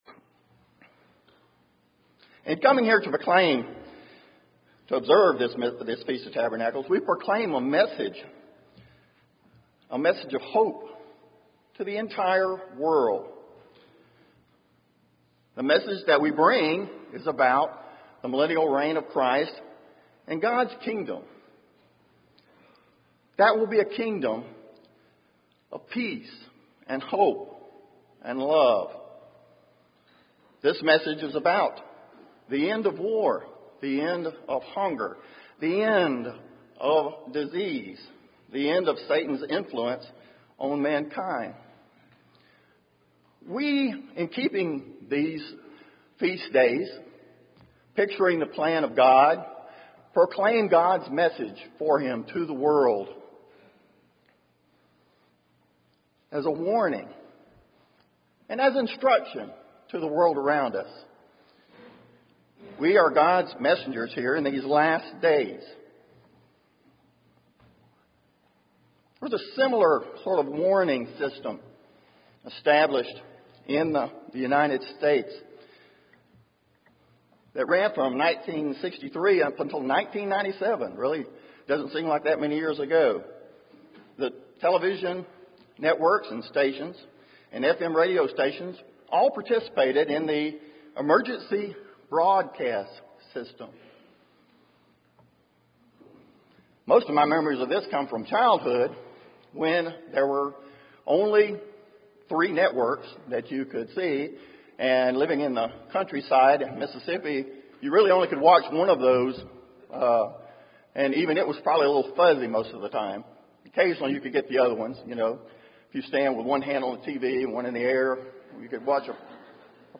This sermon was given at the Galveston, Texas 2015 Feast site.